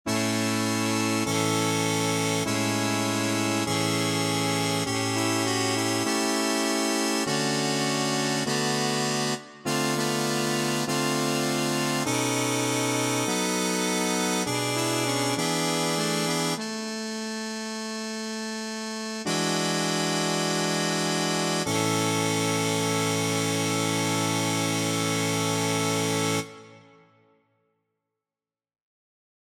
Key written in: A Major
How many parts: 4
Type: Barbershop
All Parts mix: